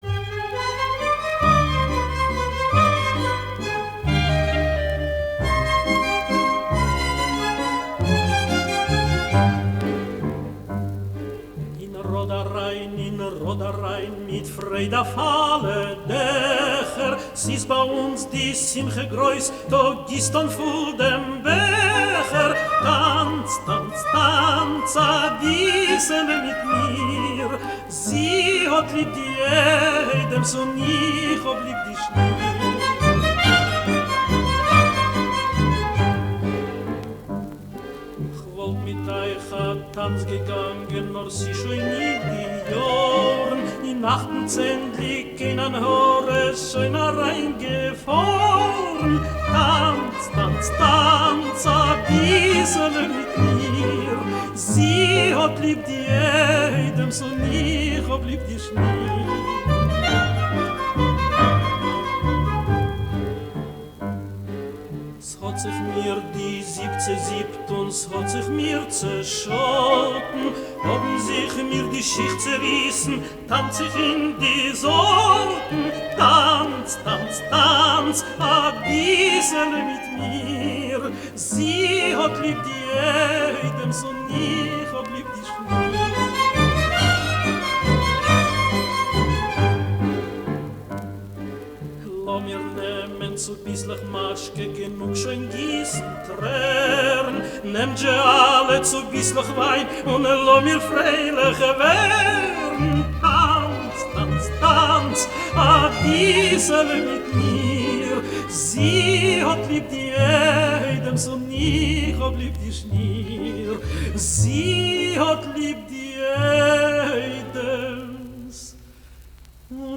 is a dance song with a lovely hora melody.